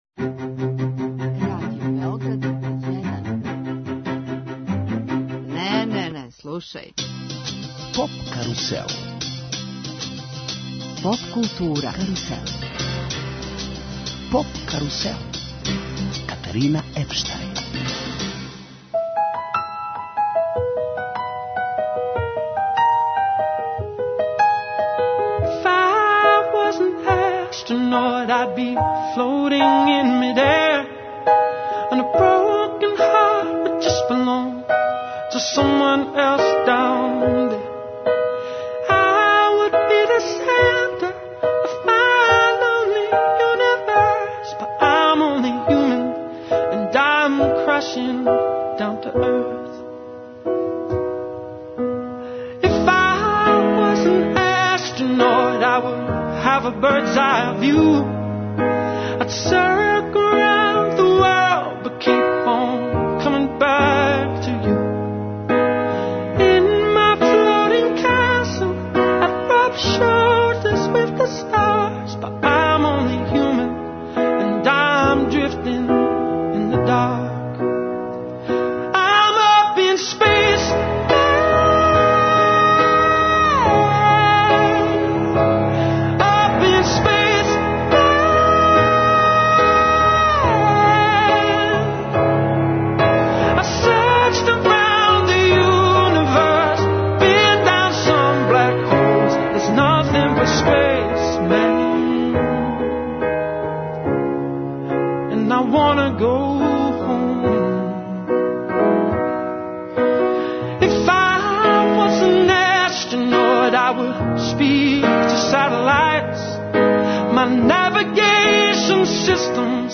Гост емисије је британски представник за Песму Евровизије, Сем Рајдер.